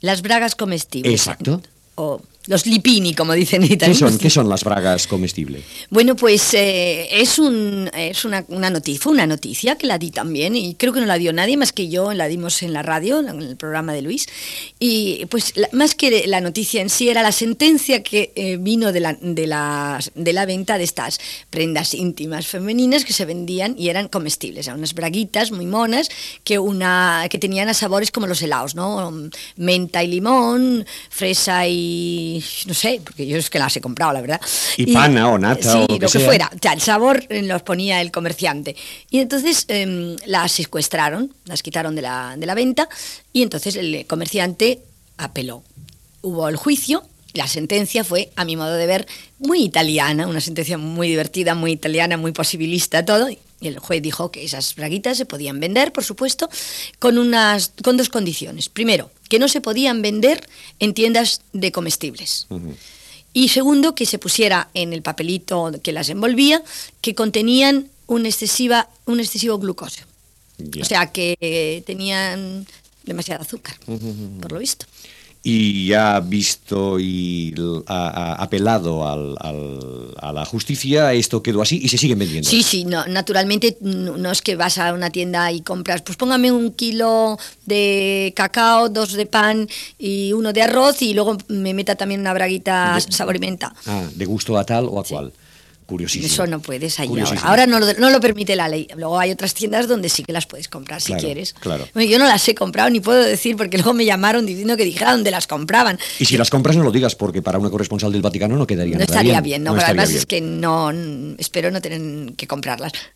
Fragment d'una entrevista a la periodista Paloma Gómez Borrero.
Entreteniment
FM